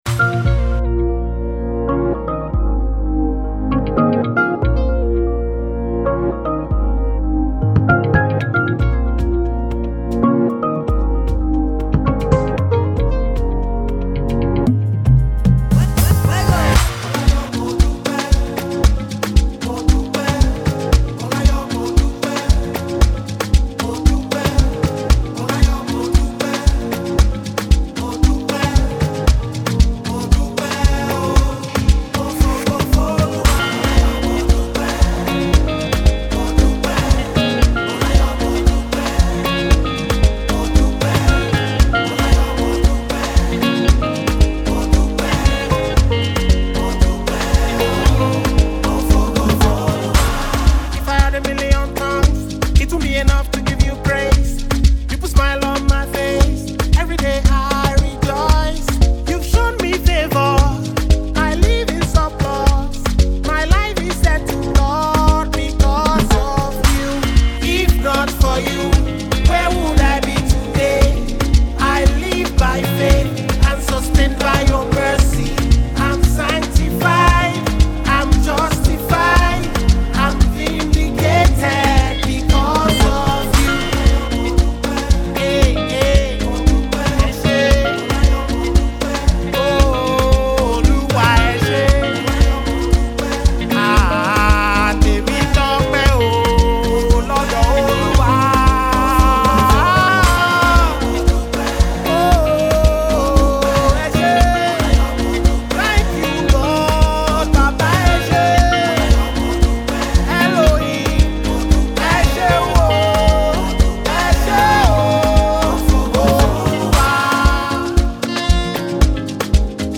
Contemporary Christian musician